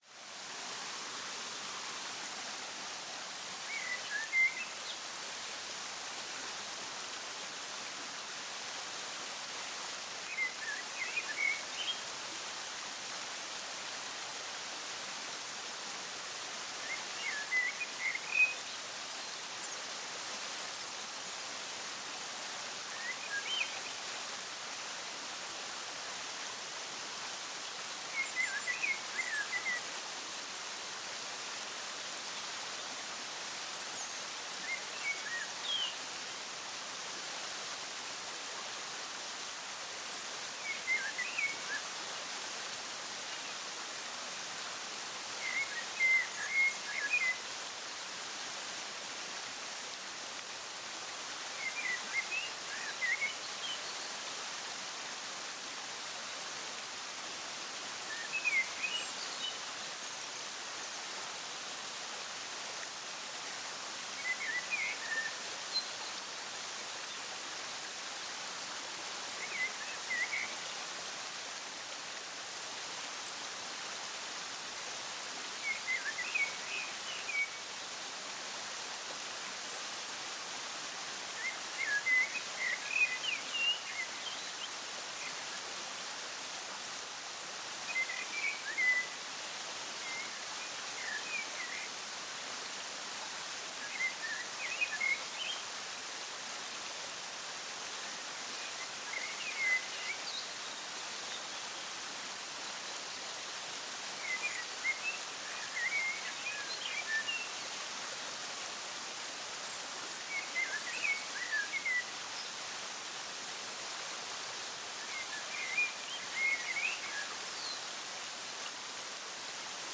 KLARE FELSENFLUTEN: Gebirgsstrom-Klarheit mit rauschenden Kristallen